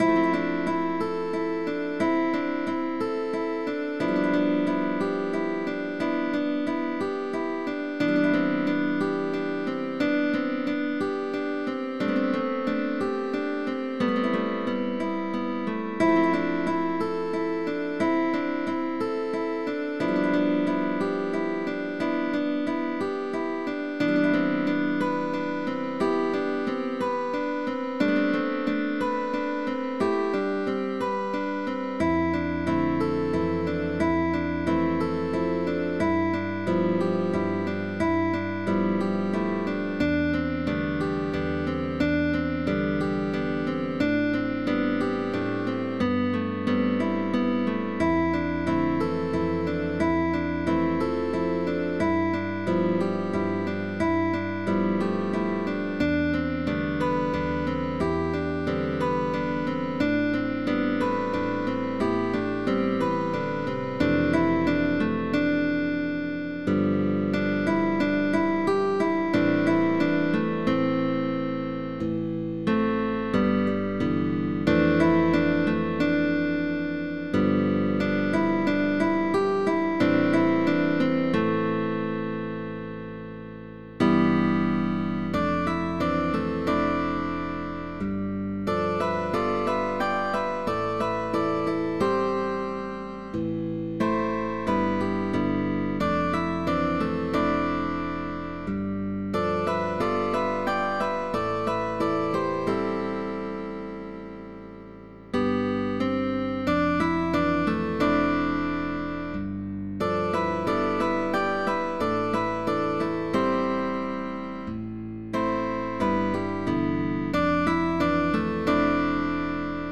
Guitar quartet sheetmusic.
GUITAR QUARTET